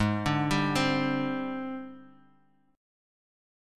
AbM7b5 Chord